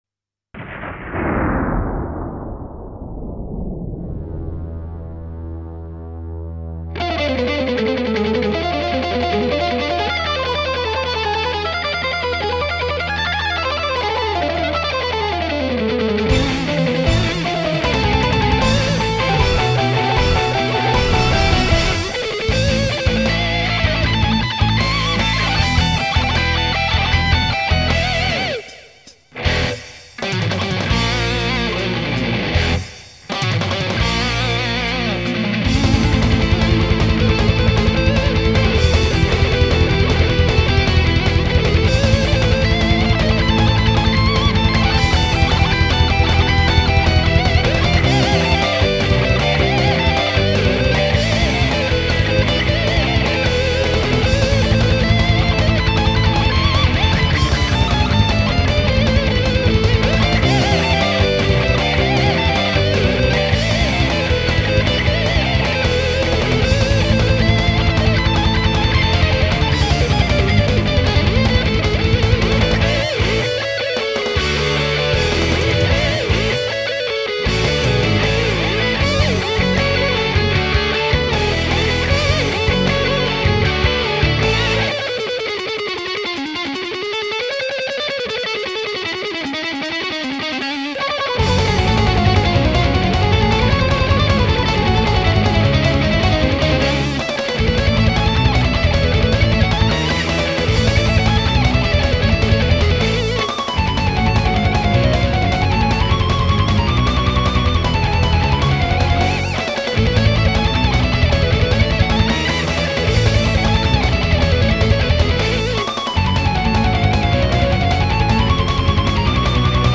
Неоклассический метал- поджанр хэви-метала под сильным влиянием классической музыки